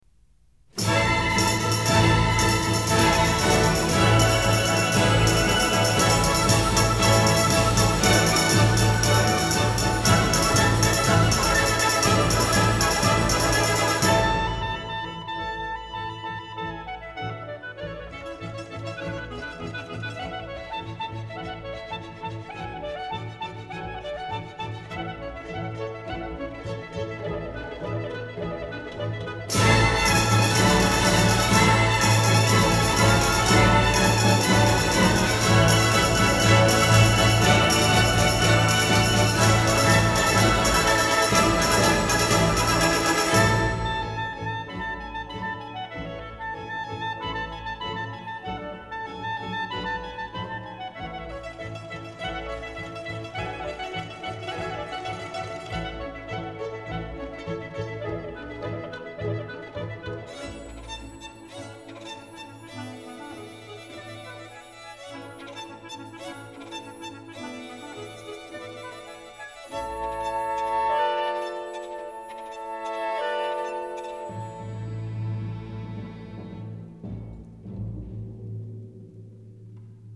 Comme je l’ai écrit plus haut, Stokowski avait tendance à bidouiller certains morceaux à sa sauce comme vous pourrez le constater dans cette Alborada du Capriccio espagnol :
Regardez la partition qui indique un tempo vif vous avez vu un ralentissement à la lettre A ?